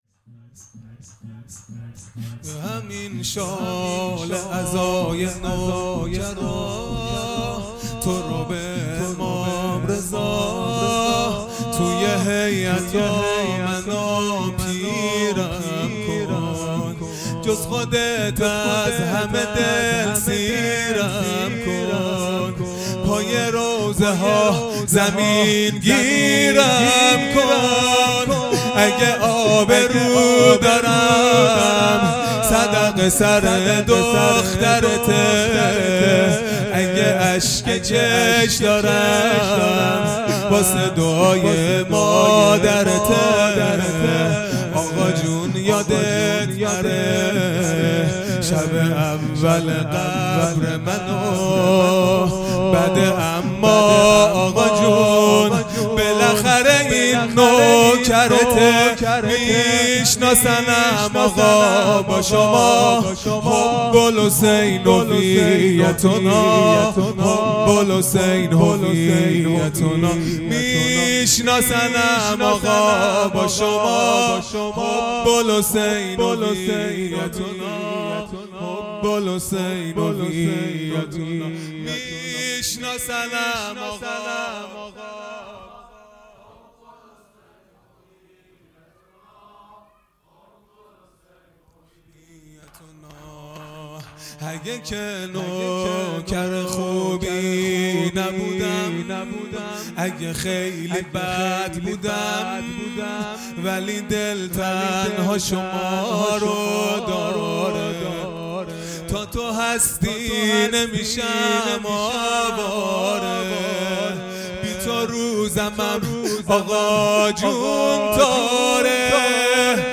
خیمه گاه - هیئت بچه های فاطمه (س) - شور | به همین شال عزای نوکرا
دهه اول محرم الحرام ۱۴۴٢ | شب اول